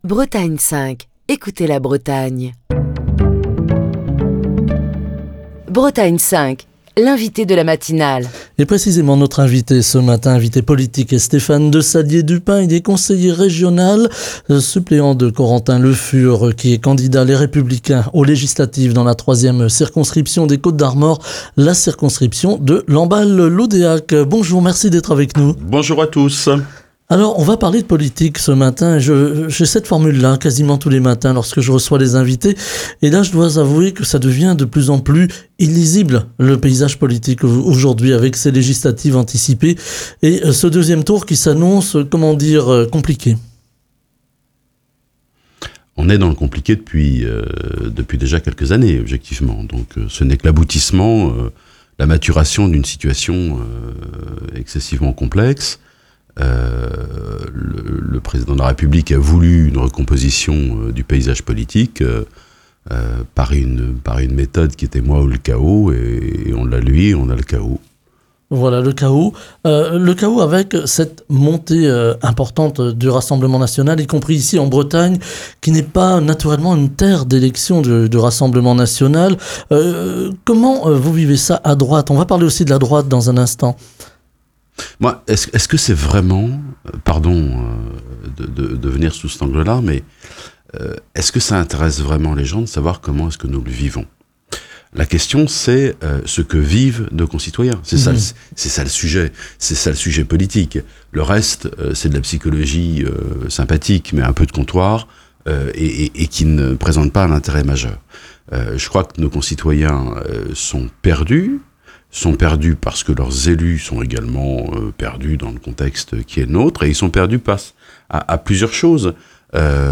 Il aborde divers domaines dans lesquels la France a reculé ces dernières années. Il plaide également pour une plus grande sérénité dans le débat public, exhortant les responsables politiques et les élus à agir dans l'intérêt général et à rester connectés aux réalités du terrain, qui diffèrent souvent des calculs politiques. Écouter Télécharger Partager le podcast Facebook Twitter Linkedin Mail L'invité de Bretagne 5 Matin